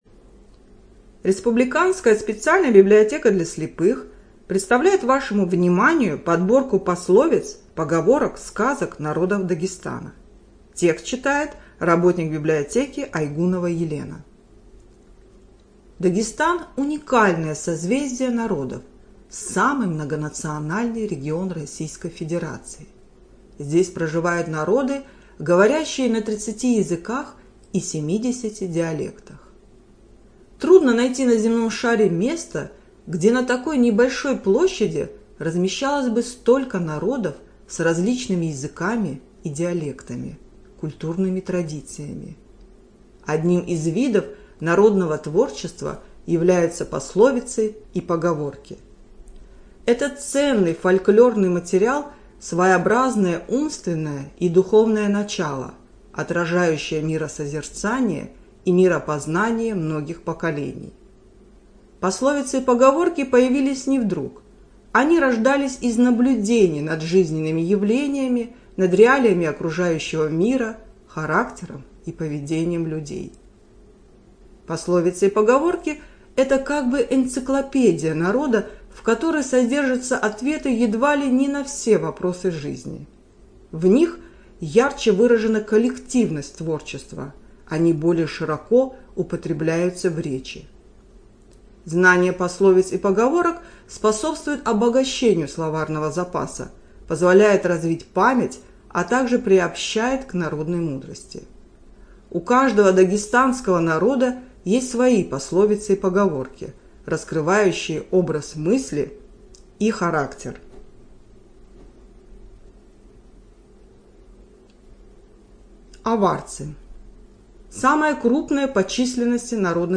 Студия звукозаписиДагестанская республиканская библиотека для слепых